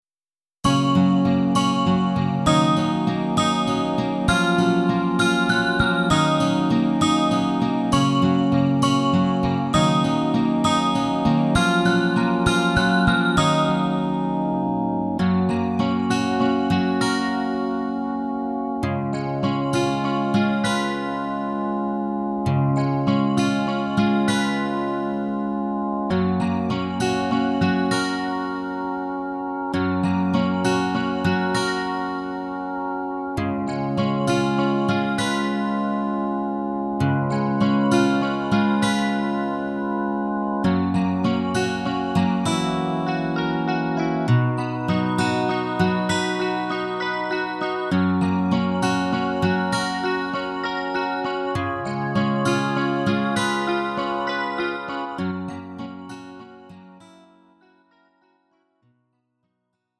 음정 -1키 3:37
장르 가요 구분 Lite MR